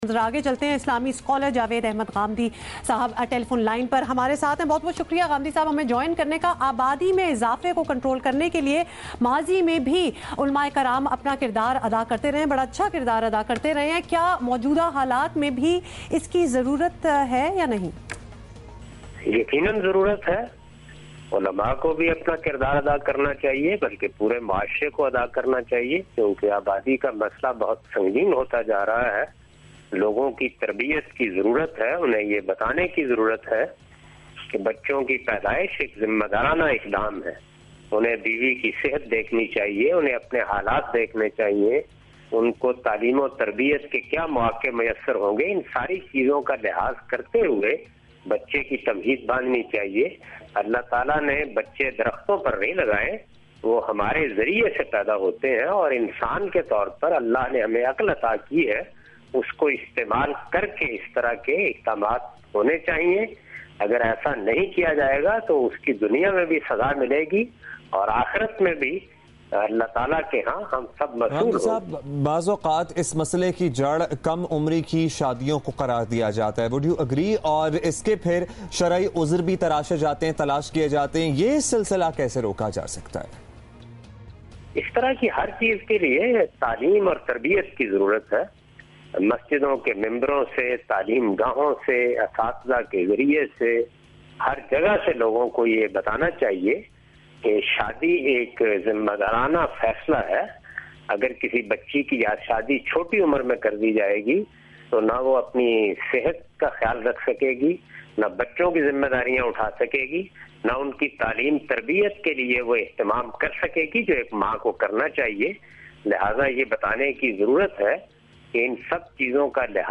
Category: TV Programs / Geo Tv / Questions_Answers /